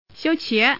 Index of /mahjong_paohuzi_Common_test1/update/1575/res/sfx/youxian/woman/